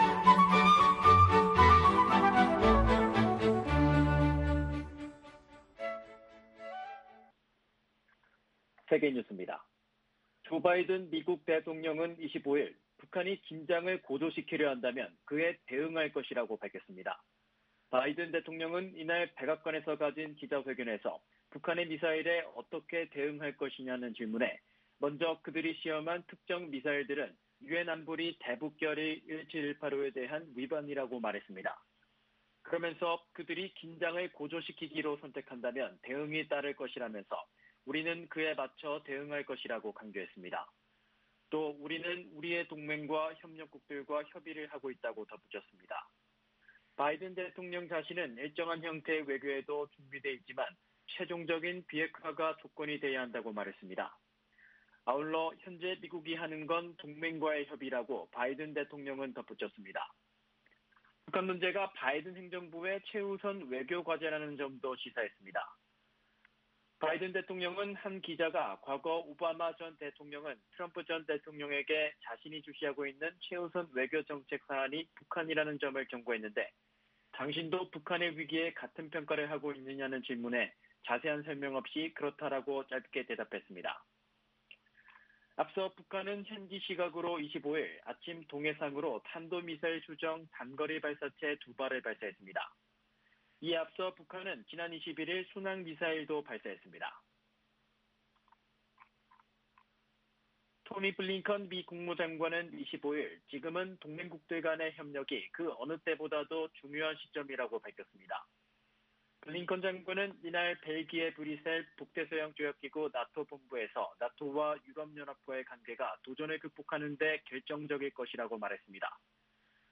VOA 한국어 아침 뉴스 프로그램 '워싱턴 뉴스 광장' 2021년 3월 26일 방송입니다. 북한이 오늘, 25일 동해상으로 단거리 탄도 미사일로 추정되는 발사체 2발을 쐈습니다.